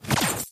Appear_Wild_Sound.mp3